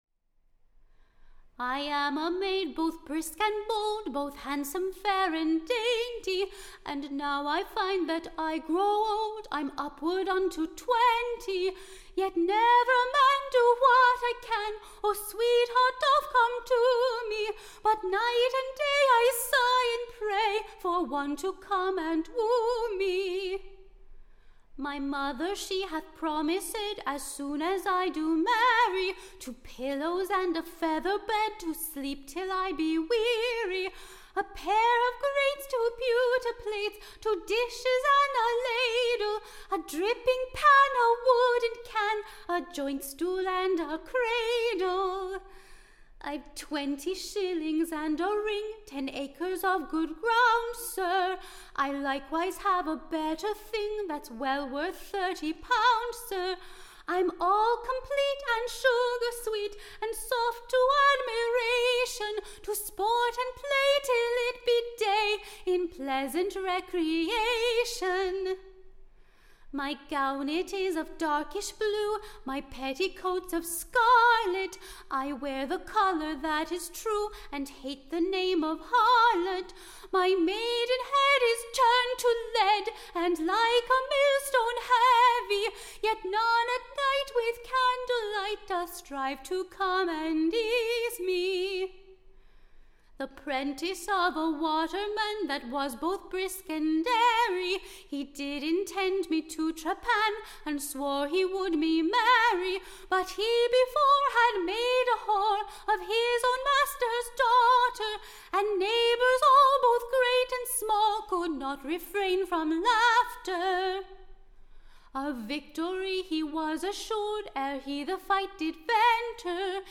Recording Information Ballad Title THE / Wanton Maid of Lambeth / AND / The Couragious Waterman. / Being a pleasant new Song, showing how much young maids desire to be married and the dan- / ger of keeping their maiden-heads too long.